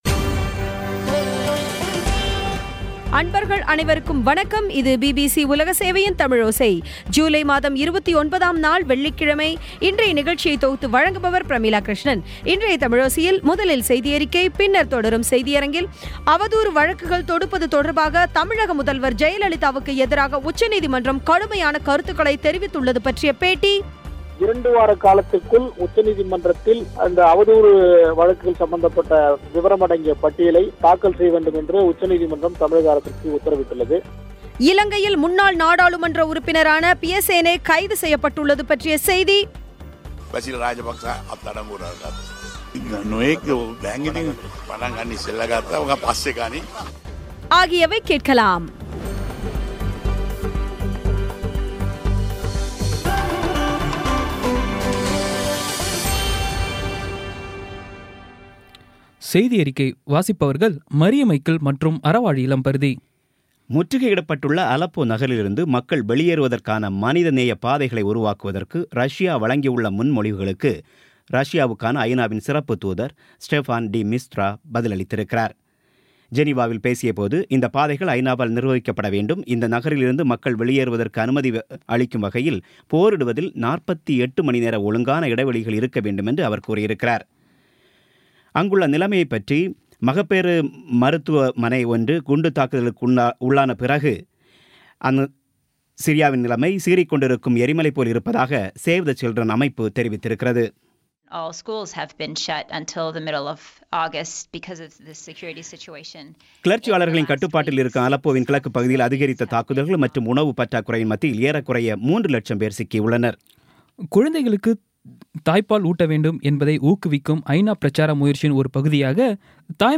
பி பி சி தமிழோசை செய்தியறிக்கை (29/07/16)